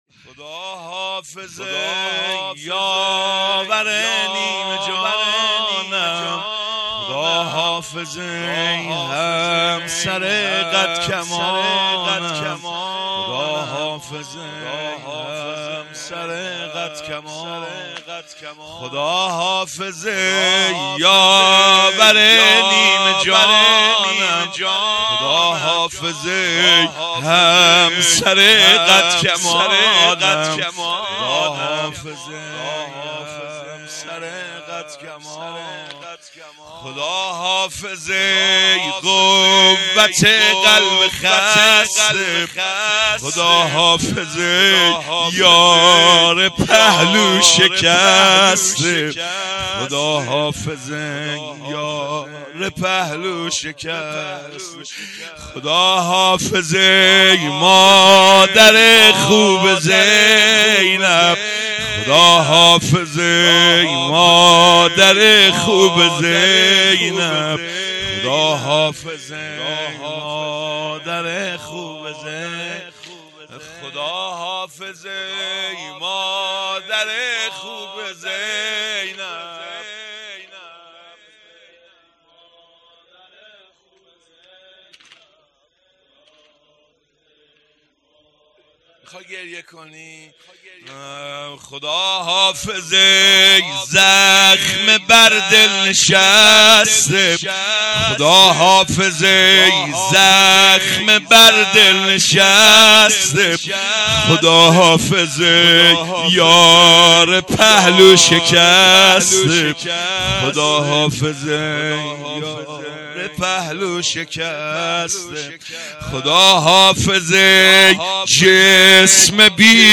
مراسم شهادت حضرت فاطمه زهرا سلام الله علیها آبان ۱۴۰۳